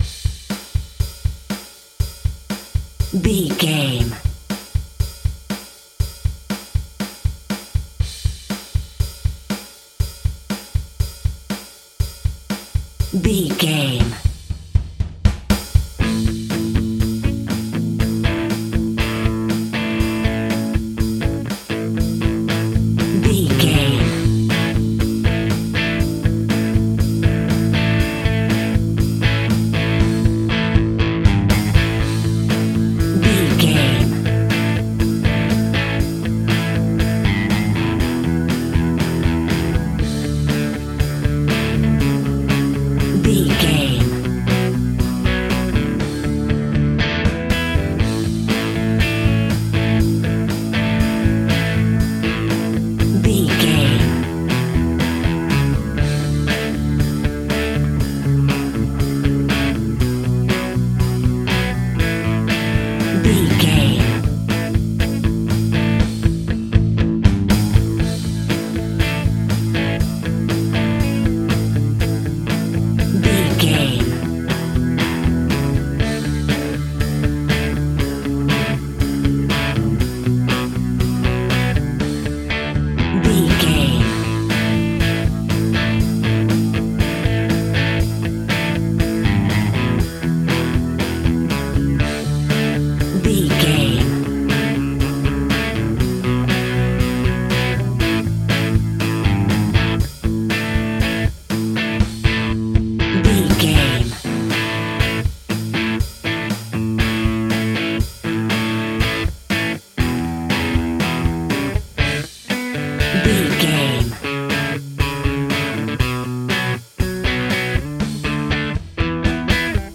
Classic 80s Rock.
Epic / Action
Fast paced
Ionian/Major
distortion
hard rock
Instrumental rock
drums
bass guitar
electric guitar
piano
hammond organ